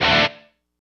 guitar stab.wav